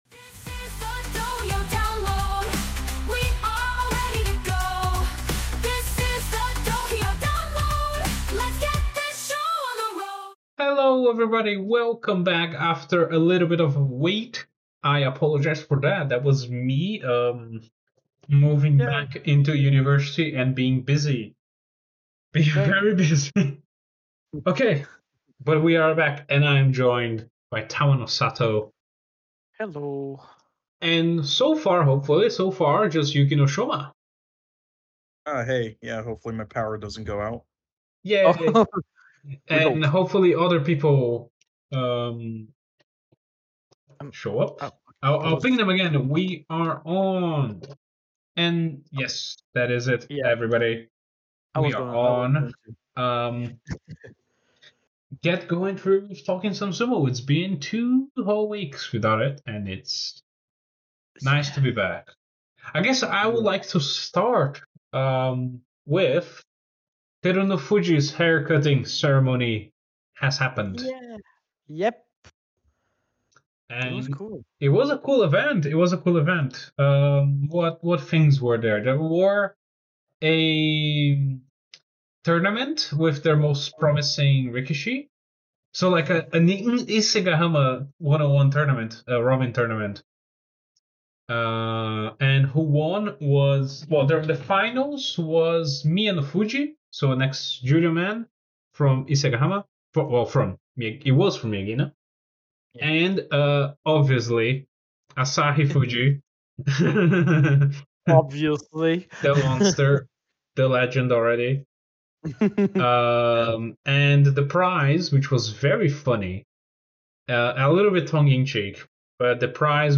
Well, if you like just some banter over funny sumo pictures, what will happen next basho and our RETURN TO CHAMP CHUMP, then this is Dohyo Download episode is for you!